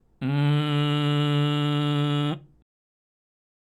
次に、喉頭は巨人の状態でグーの声を使いながら鼻、鼻口、口の順に出していく。
※喉頭は巨人の状態でグーで鼻